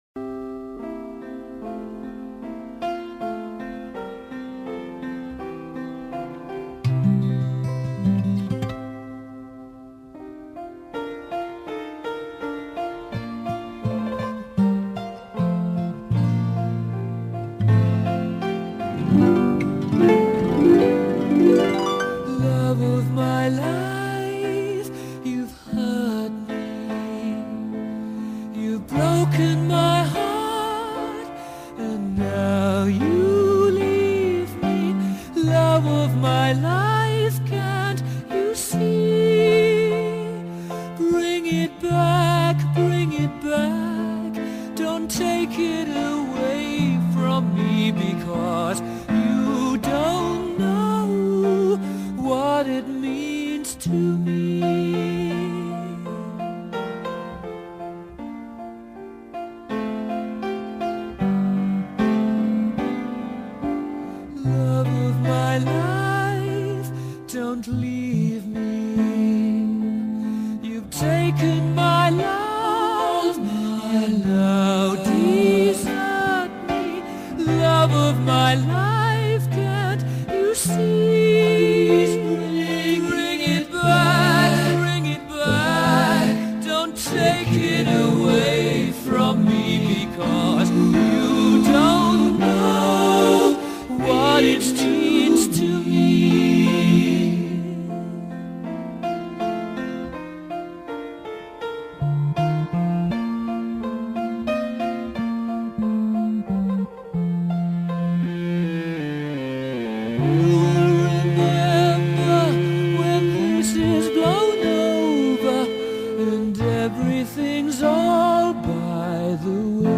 ballada